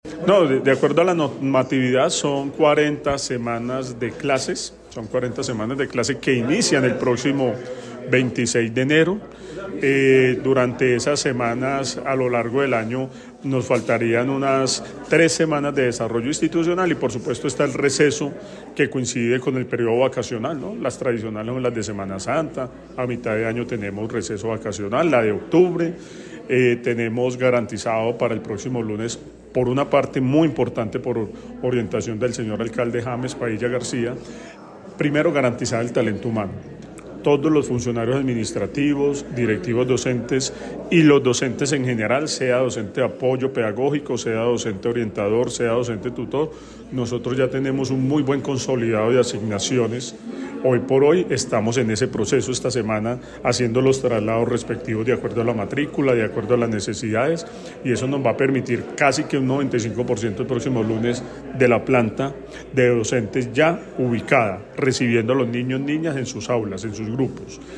Secretario de Educación de Armenia, Antonio José Veléz, inicio de clases